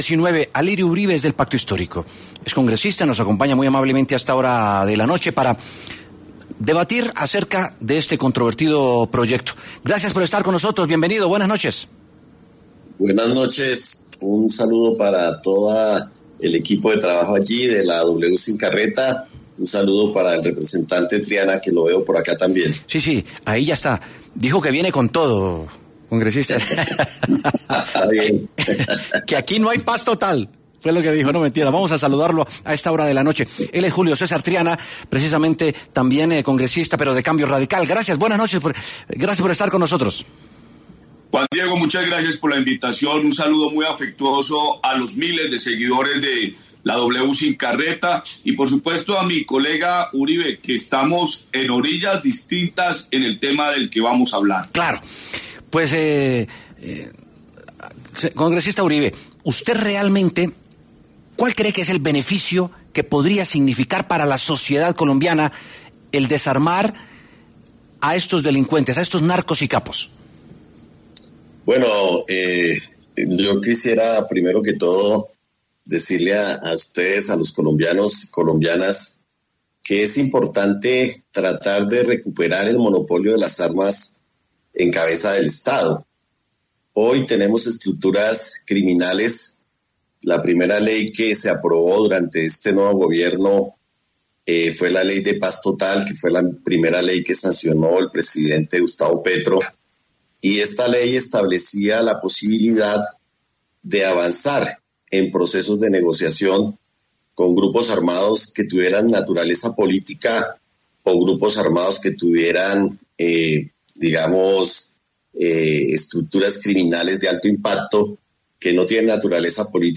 Debate | ¿Es viable el proyecto de paz total? Alirio Uribe y Julio César Triana responden